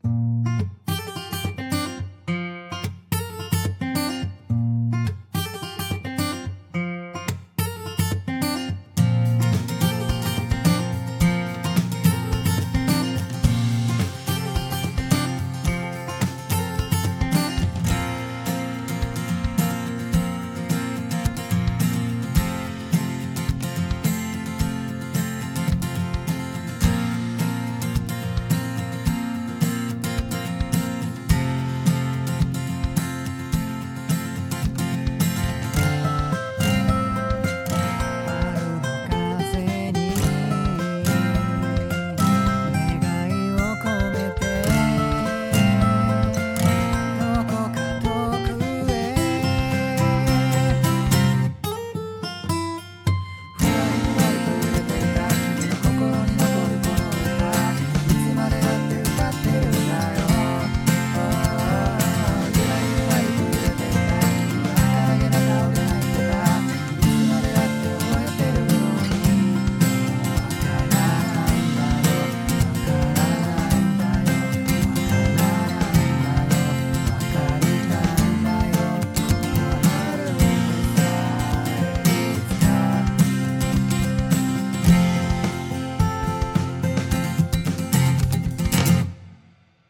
【声劇台本】秋めくオレンジ / ■貴方×□貴女